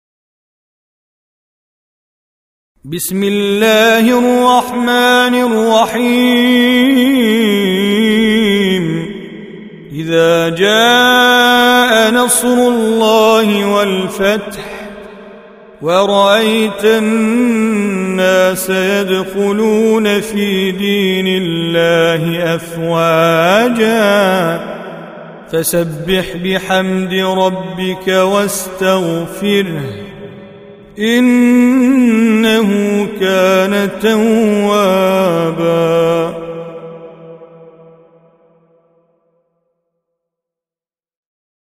Audio Quran Tajweed Recitation
Surah Sequence تتابع السورة Download Surah حمّل السورة Reciting Mujawwadah Audio for 110. Surah An-Nasr سورة النصر N.B *Surah Includes Al-Basmalah Reciters Sequents تتابع التلاوات Reciters Repeats تكرار التلاوات